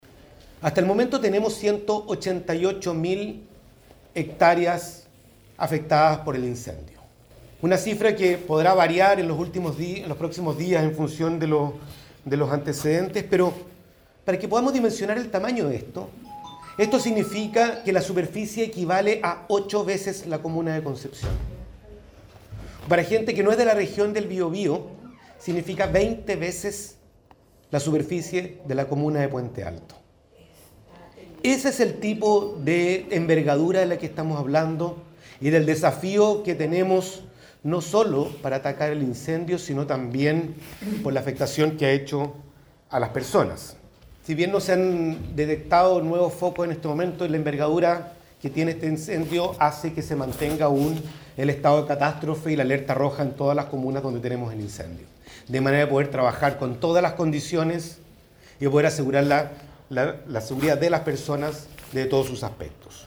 “Tenemos aún 12 incendios en combate, cifra similar al día de ayer, lo mismo que 15 incendios controlados. Si bien muestra que hay trabajo por hacer, da cuenta de la capacidad que ha tenido Conaf, Bomberos, brigadistas y la gran colaboración de los brigadistas extranjeros para controlar una situación de esta magnitud”, informó durante la mañana de este lunes, el ministro de Obras Públicas y enlace del gobierno en Biobío, Juan Carlos García.